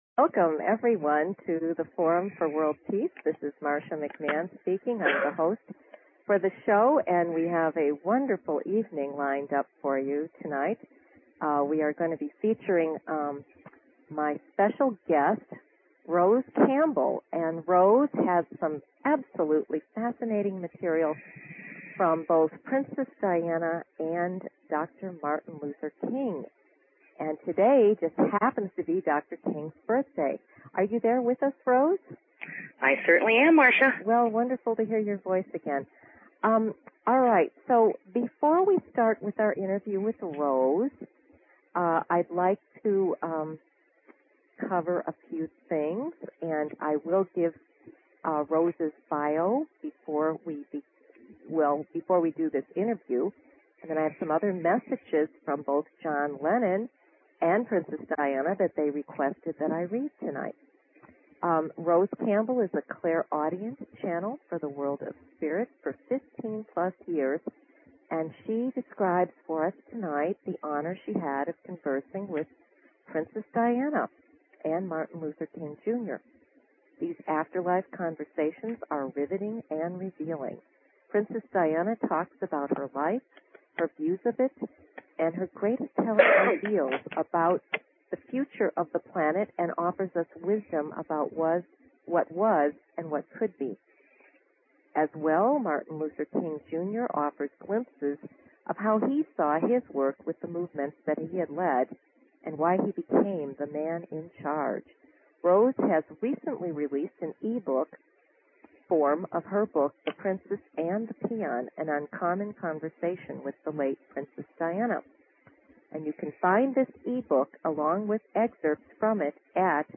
Talk Show Episode, Audio Podcast, Forum For World Peace and Courtesy of BBS Radio on , show guests , about , categorized as